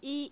i as in eat